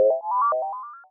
computer_c.wav